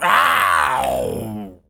pgs/Assets/Audio/Animal_Impersonations/tas_devil_cartoon_05.wav at master
tas_devil_cartoon_05.wav